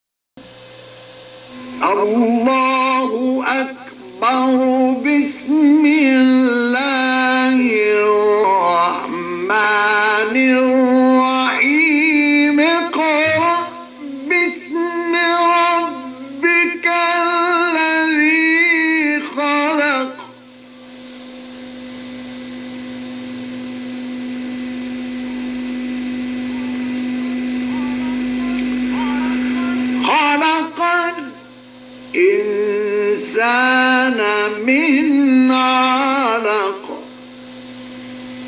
گروه فعالیت‌های قرآنی: مقاطع صوتی دلنشین از قراء بین‌المللی جهان اسلام را می‌شنوید.